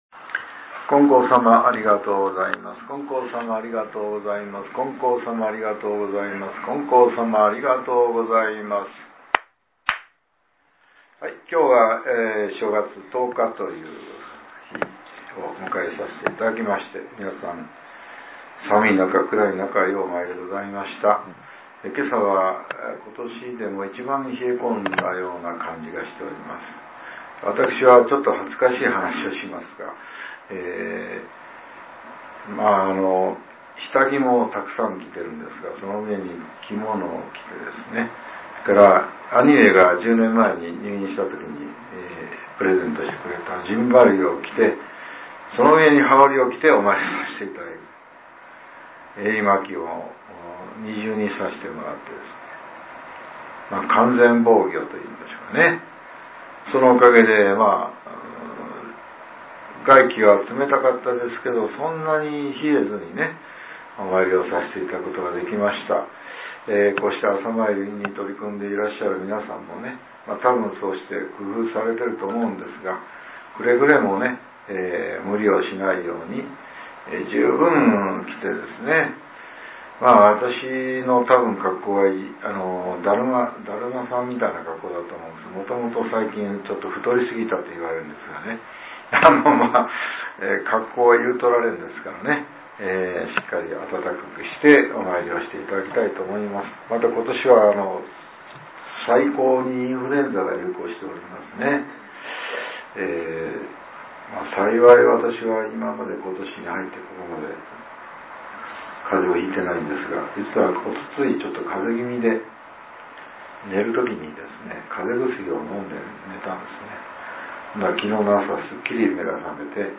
令和７年１月１０日（朝）のお話が、音声ブログとして更新されています。